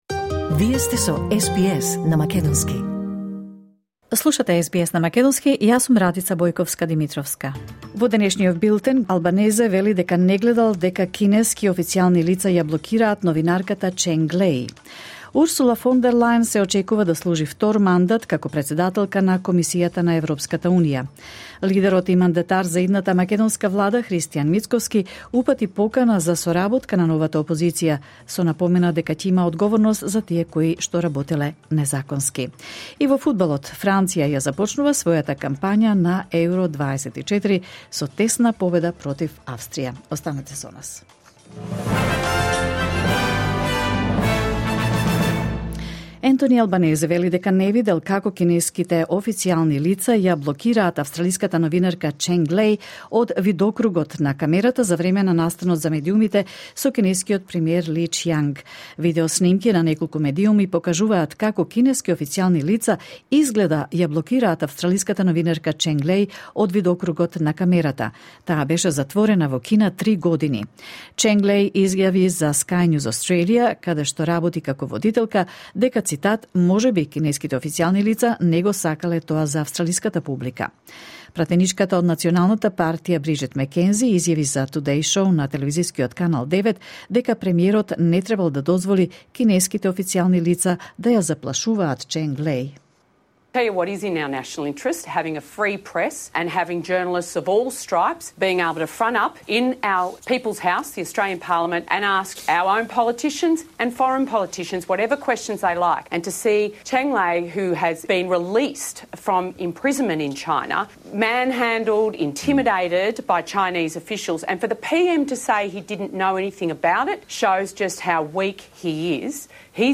Вести на СБС на македонски 18 јуни 2024
SBS News in Macedonian 18 June 2024